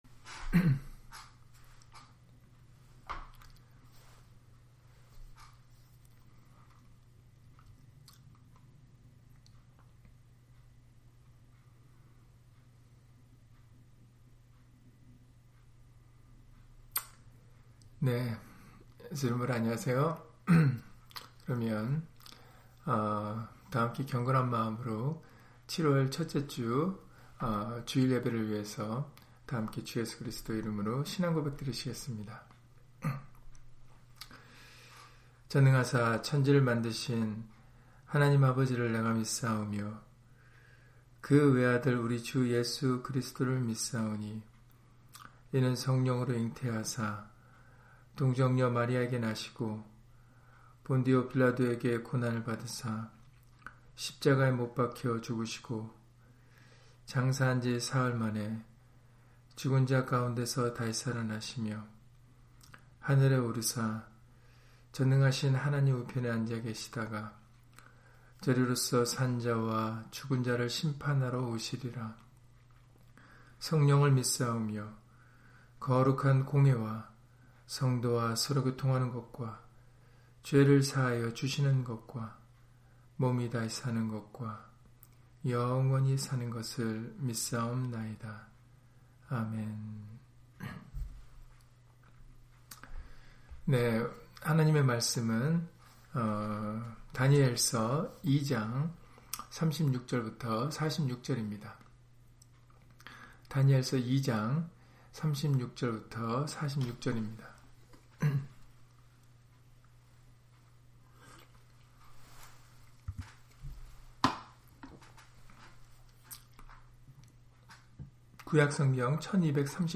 다니엘 2장 36-46절 [하늘의 하나님이 한 나라를 세우시리니] - 주일/수요예배 설교 - 주 예수 그리스도 이름 예배당